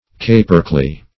Search Result for " capercally" : The Collaborative International Dictionary of English v.0.48: Capercailzie \Ca"per*cail`zie\, or Capercally \Ca"per*cal`ly\, n. [Gael, capulcoile.] (Zool.) A species of black Old World grouse ( Tetrao uragallus ) of large size and fine flavor, found in northern Europe and formerly in Scotland; -- called also cock of the woods and horse of the wood .